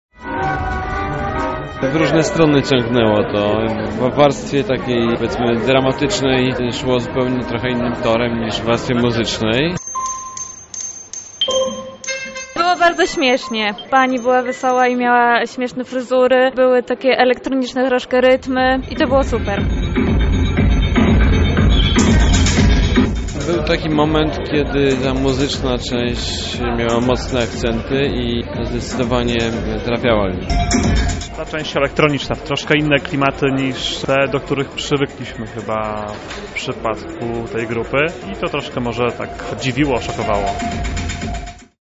Zapytaliśmy lubelską publiczność o wrażenia po koncercie.